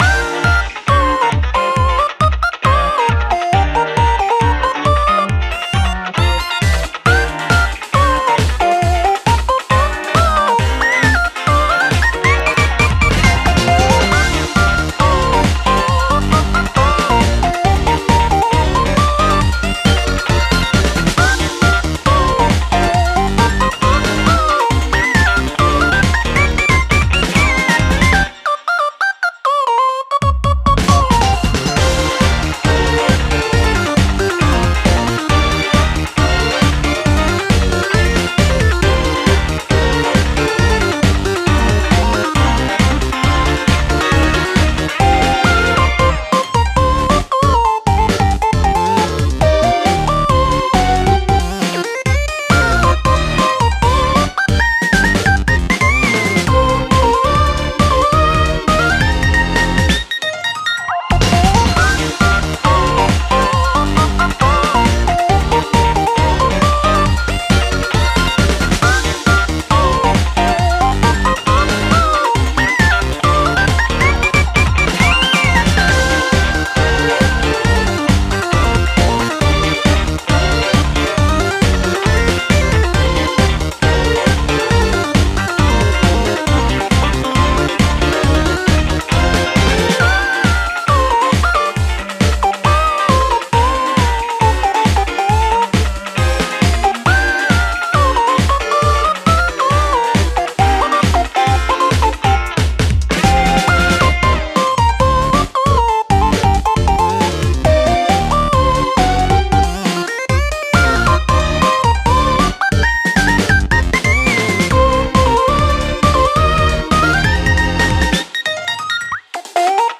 ループ用音源（BPM=136）
ループ本体
• ループ本体 隙間なく繋げていくと無限にループできるように編集した音源。